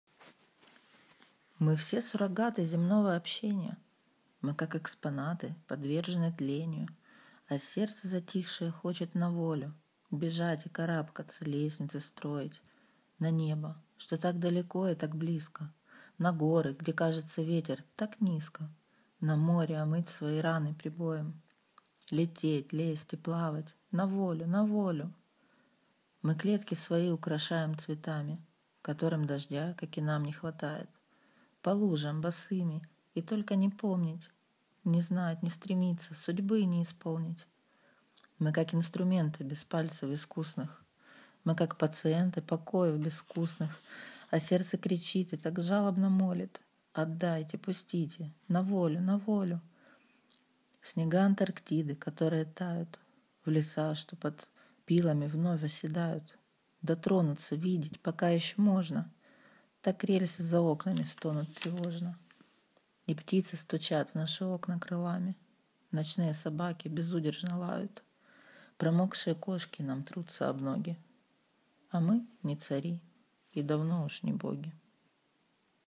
Хорошее прочтение, приятный голос, умилили перелистываемые страницыsmile12